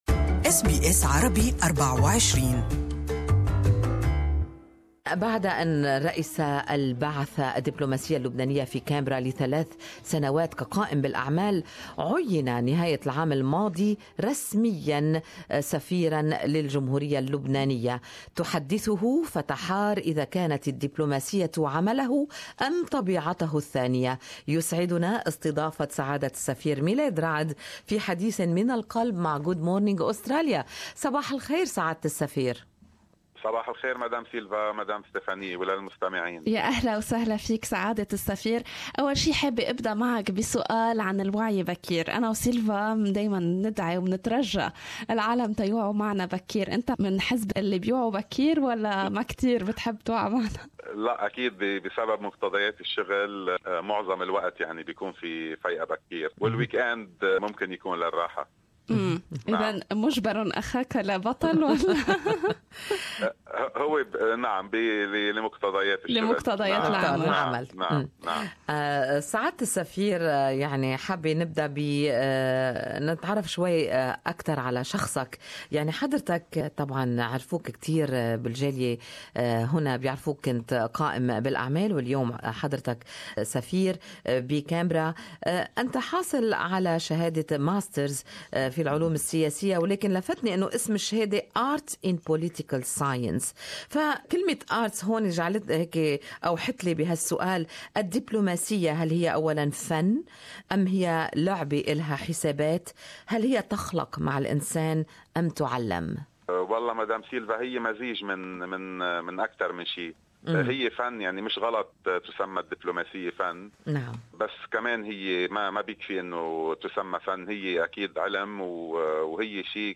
New Lebanese Ambassador to Canberra Milad Raad speaks to Good Morning Australia about the Lebanese community in Australia and the challenges of his new job.